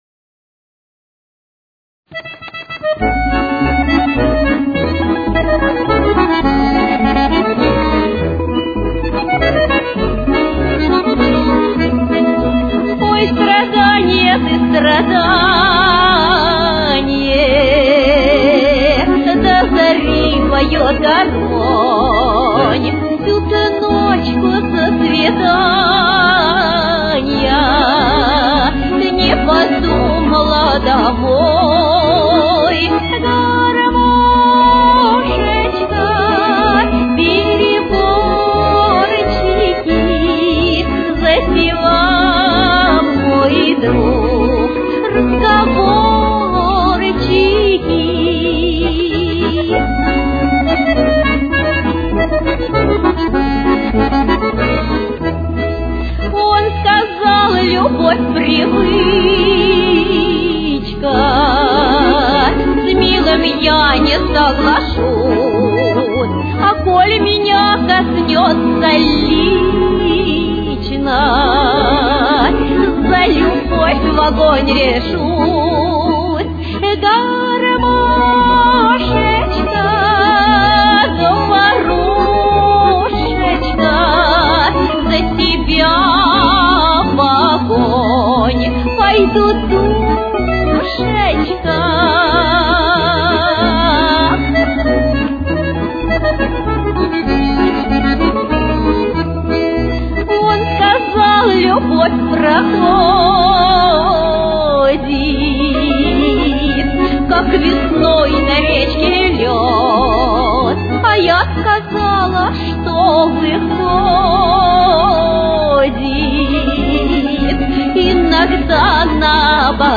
Темп: 94.